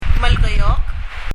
ネイティブ・パラワンの発音を聴いてみましょう
ネイティブ・パラワンの発音を聴いてみましょう しいてカナ表記するとすれば、「マルケヨーク」でしょうか？？